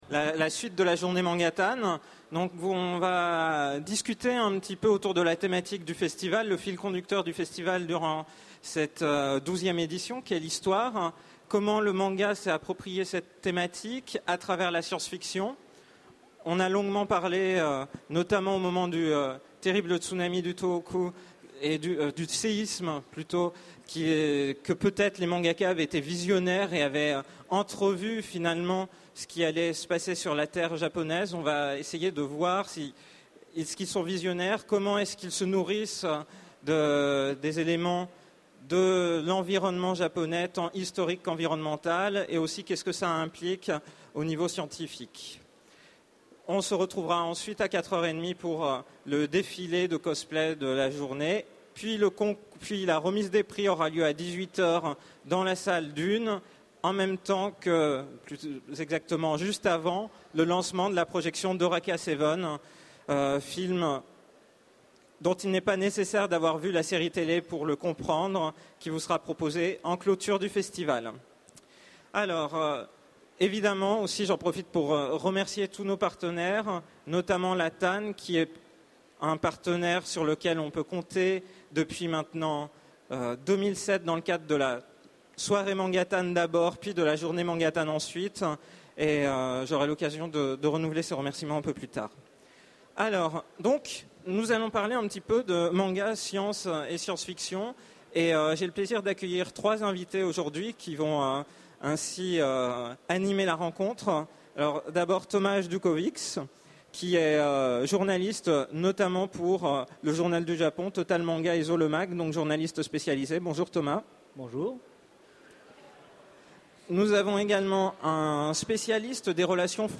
Utopiales 2011 : Conférence Histoire de la science fiction dans le manga